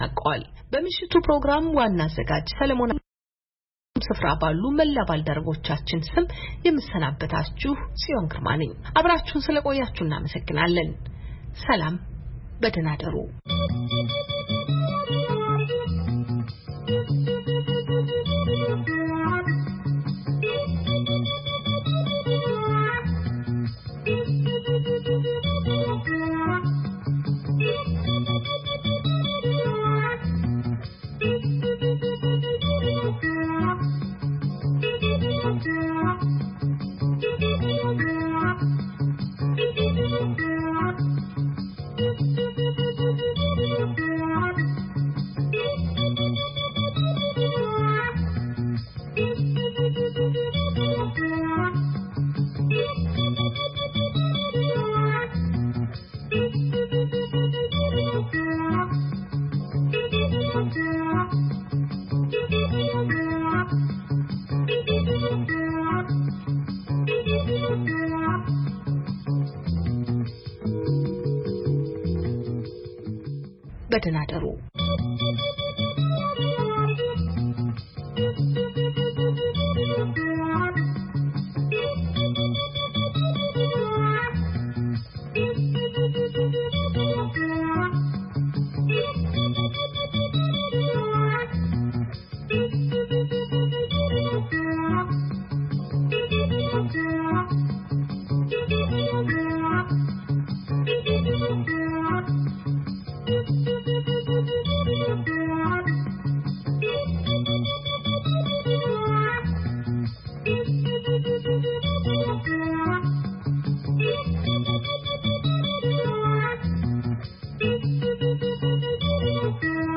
ፈነወ ትግርኛ ብናይ`ዚ መዓልቲ ዓበይቲ ዜና ይጅምር ። ካብ ኤርትራን ኢትዮጵያን ዝረኽቦም ቃለ-መጠይቓትን ሰሙናዊ መደባትን ድማ የስዕብ ። ሰሙናዊ መደባት ረቡዕ፡ ህዝቢ ምስ ህዝቢ